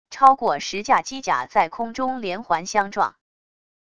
超过十架机甲在空中连环相撞wav音频